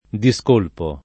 discolpo [ di S k 1 lpo ]